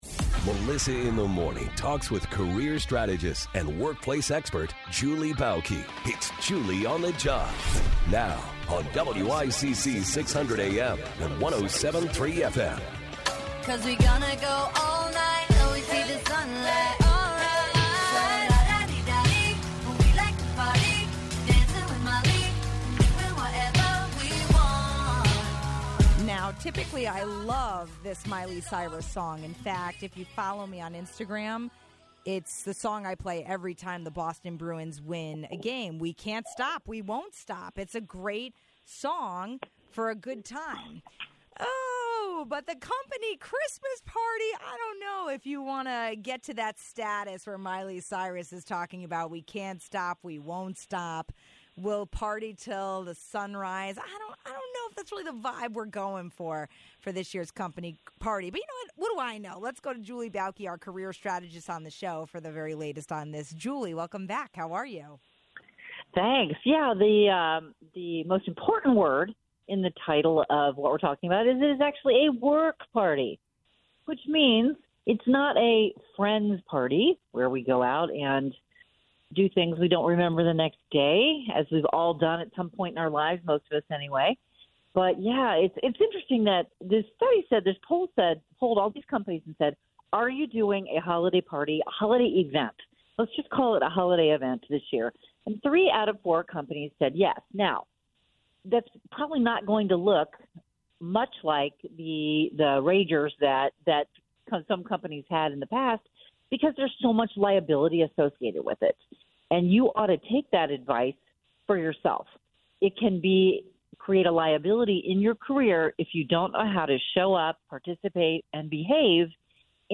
So, what can Connecticut lawmakers do to give residents some financial relief? We asked Senator Ryan Fazio. (17:16) Are you allergic to your Christmas tree?